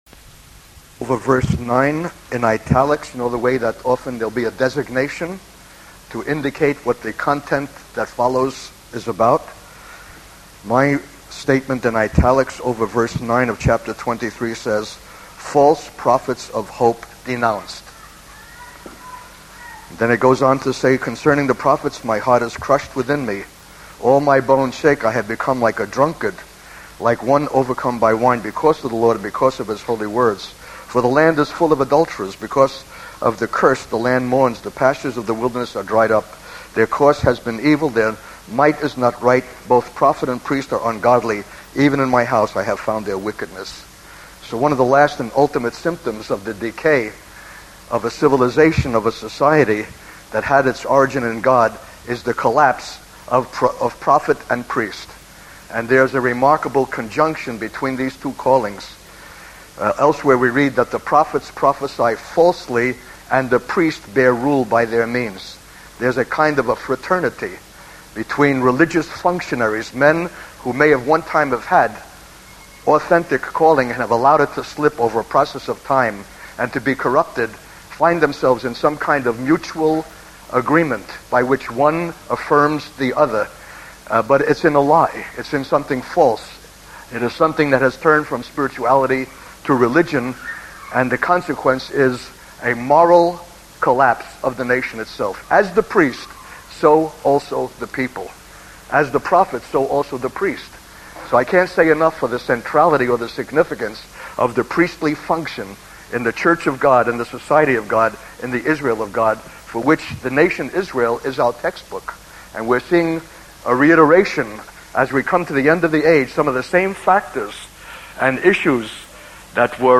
In this sermon, the speaker addresses a controversy within their fellowship involving a charismatic believer who had different expectations. The speaker emphasizes the importance of seeking the counsel of the Lord and not projecting our own ideas onto God. They warn against false prophets who speak falsehoods and lying divinations, claiming to speak for God when they have not been sent by Him.